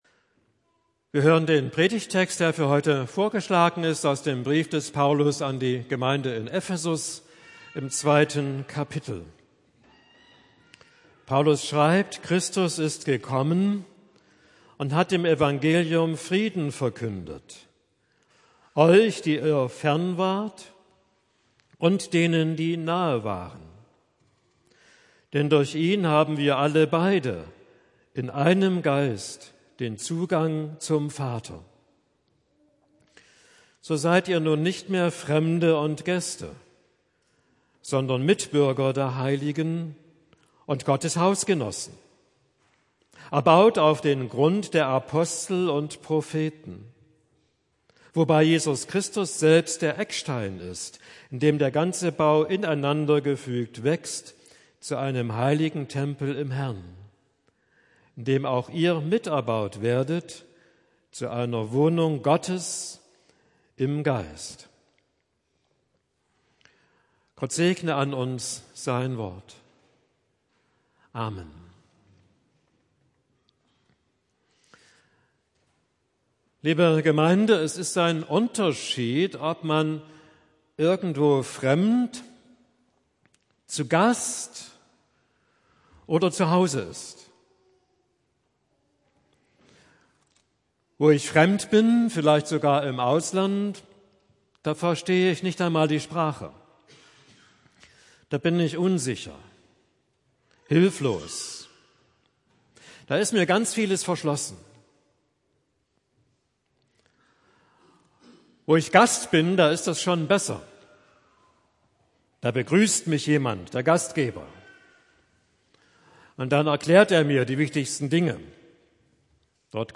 Predigt für den 2. Sonntag nach Trinitatis